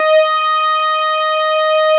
Added synth instrument
snes_synth_063.wav